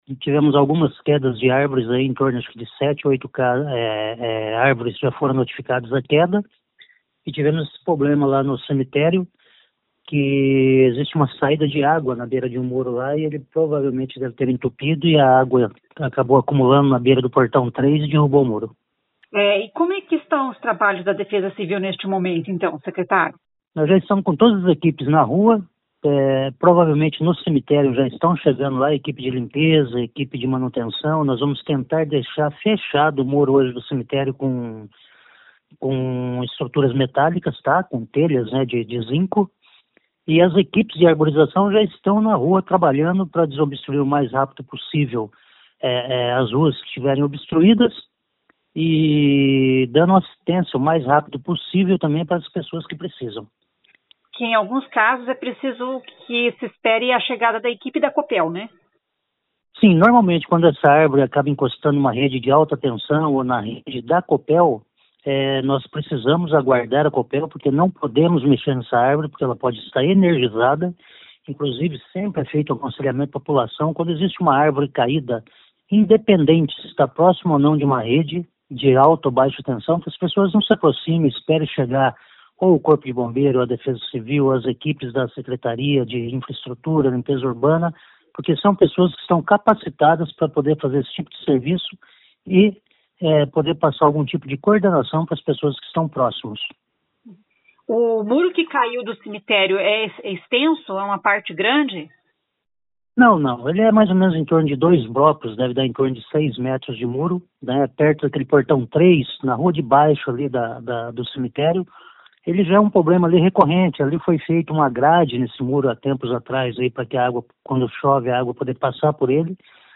Ouça o que diz o secretário: